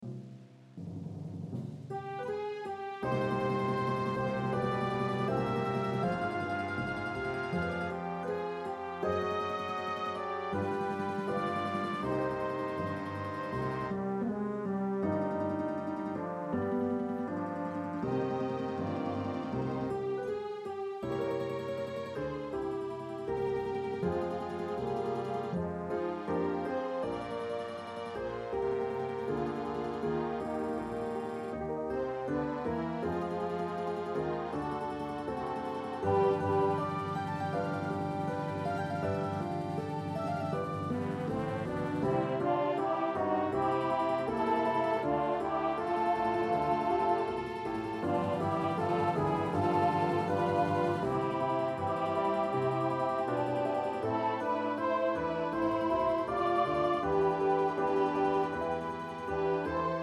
Χορωδιακά